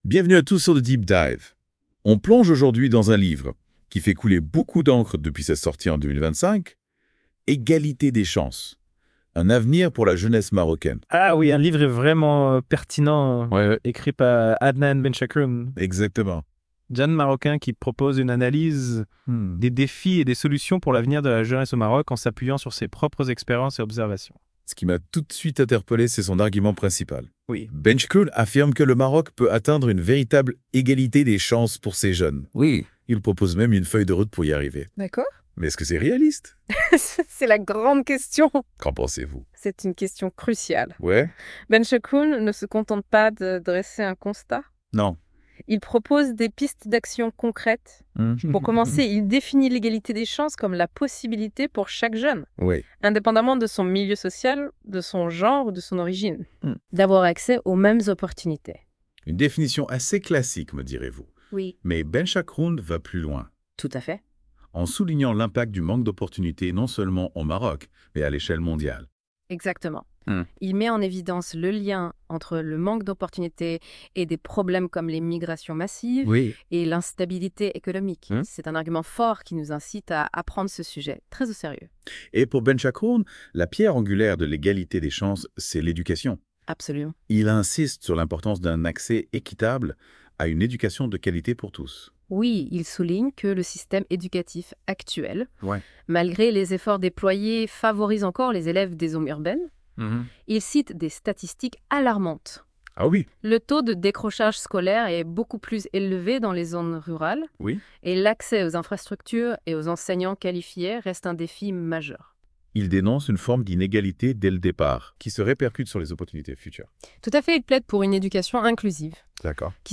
Débat